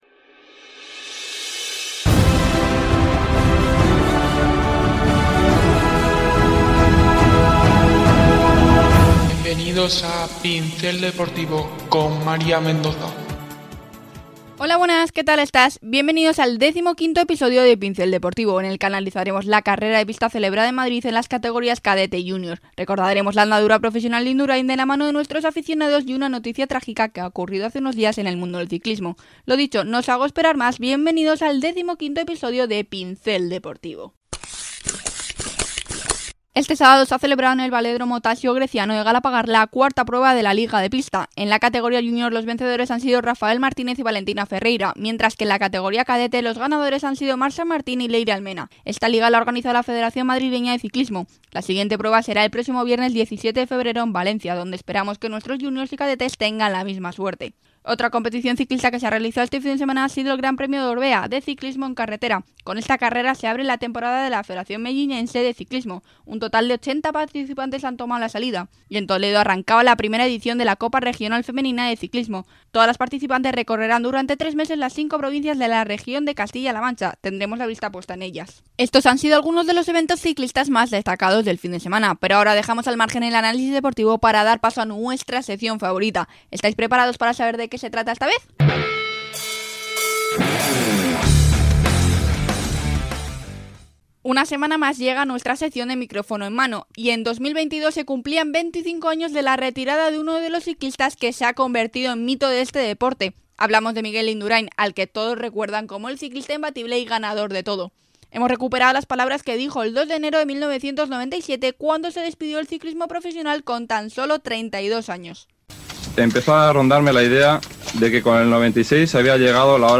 En el decimoquinto episodio de Pincel Deportivo analizamos las distintas competiciones de Ciclismo de este fin de semana. Hablamos con los aficionados sobre la carrera profesional de Induráin y muchas sorpresas más.